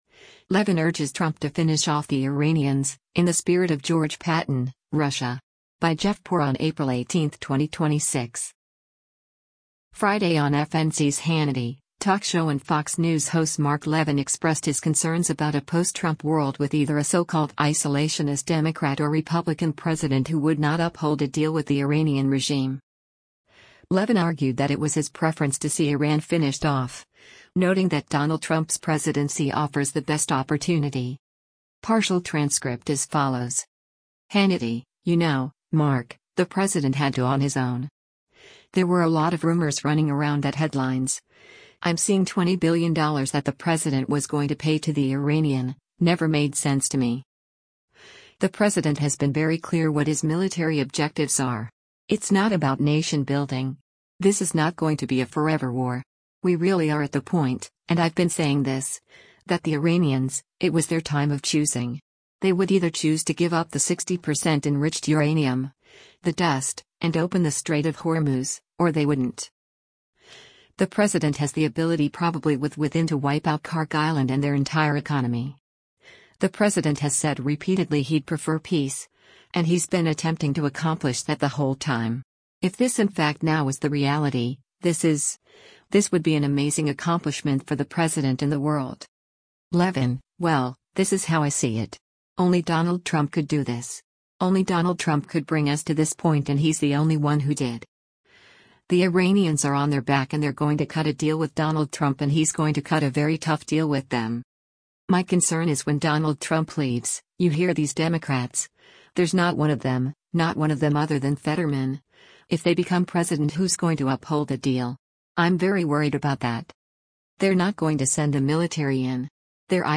Friday on FNC’s “Hannity,” talk show and Fox News host Mark Levin expressed his concerns about a post-Trump world with either a so-called “isolationist” Democrat or Republican president who would not uphold a deal with the Iranian regime.